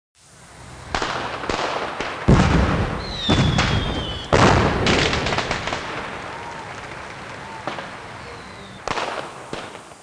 SFX鞭炮声(爆竹烟花声)音效下载
SFX音效